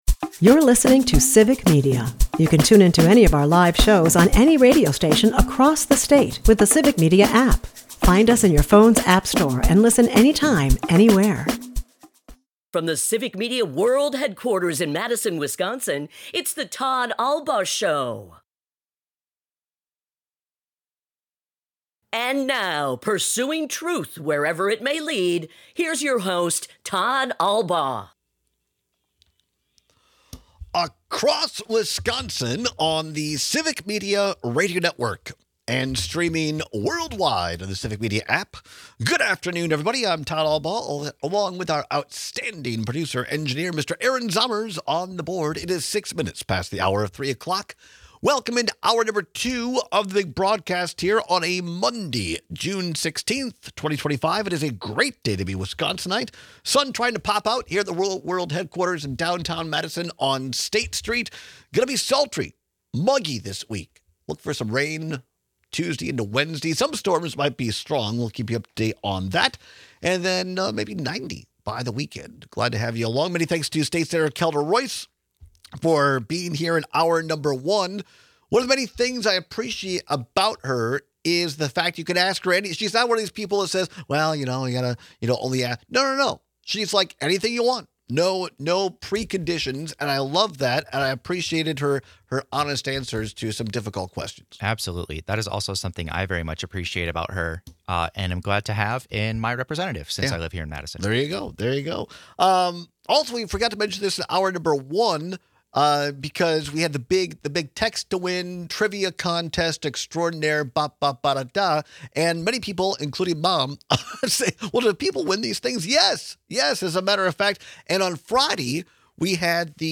airs live Monday through Friday from 2-4 pm across Wisconsin